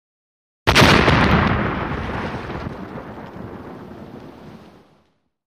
Звуки гранаты
Реалистичный звук взрыва гранаты